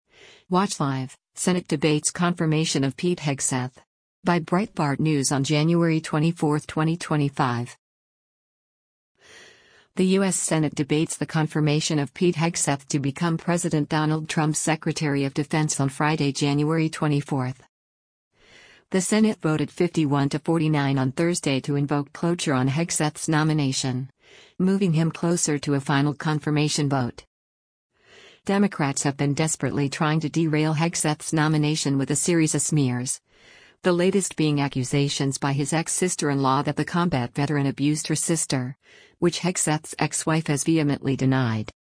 The U.S. Senate debates the confirmation of Pete Hegseth to become President Donald Trump’s Secretary of Defense on Friday, January 24.